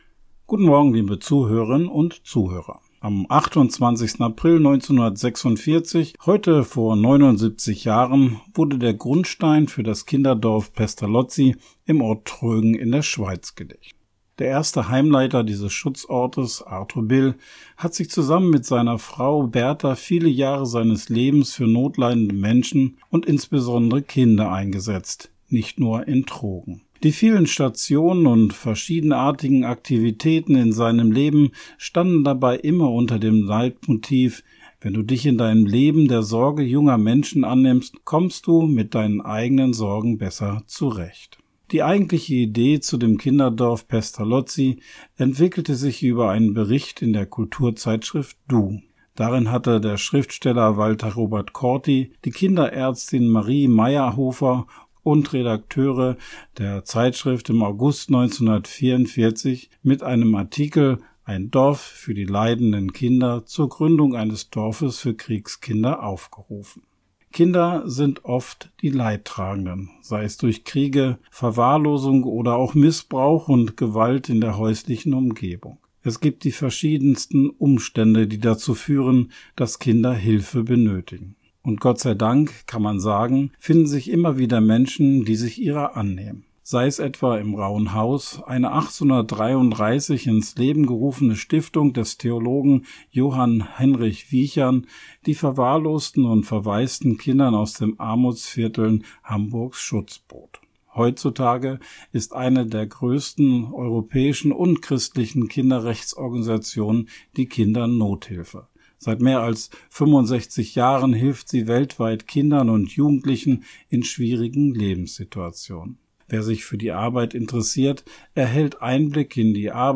Radioandacht vom 28. April